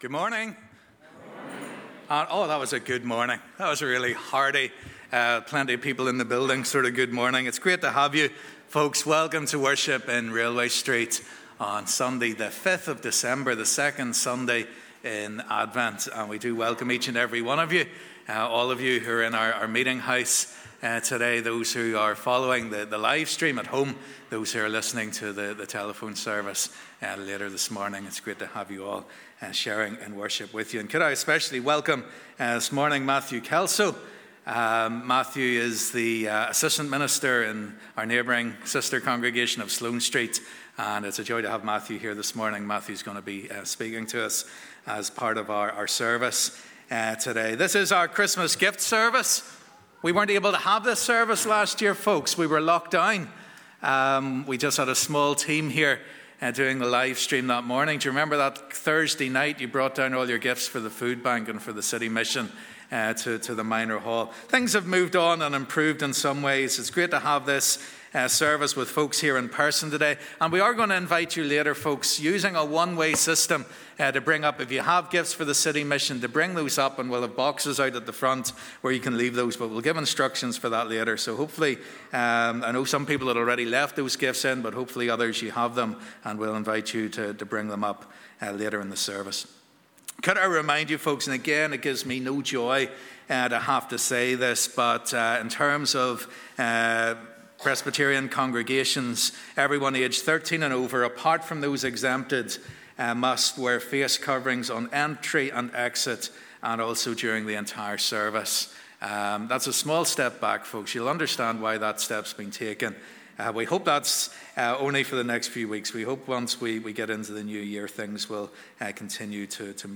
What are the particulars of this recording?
Christmas Gift Family Service Welcome to our first live in person Christmas Gift Service since 2019!